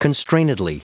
Prononciation du mot constrainedly en anglais (fichier audio)
Prononciation du mot : constrainedly